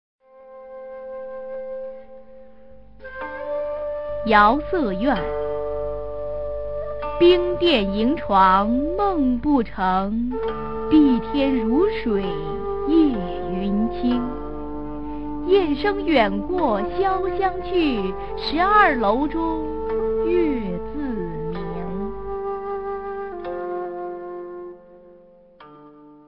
[隋唐诗词诵读]温庭筠-瑶瑟怨a 配乐诗朗诵